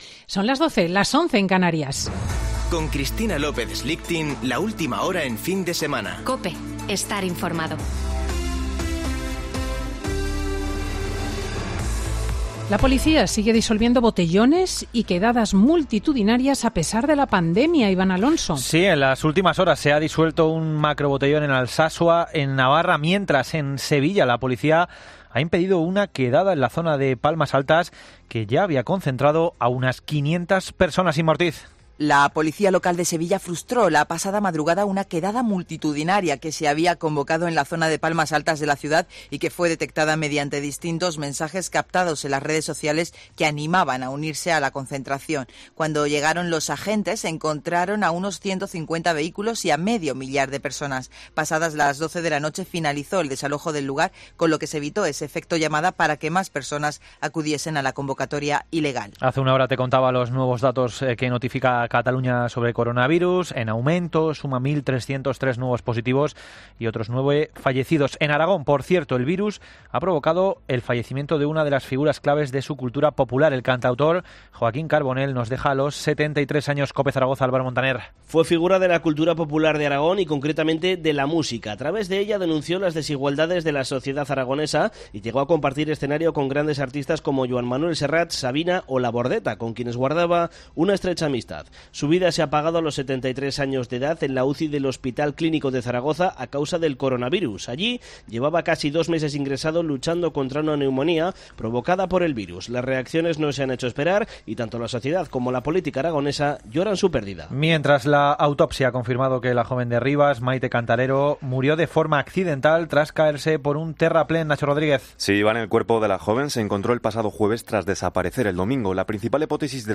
Boletín de noticias de COPE del 12 de septiembre de 2020 a las 12.00 horas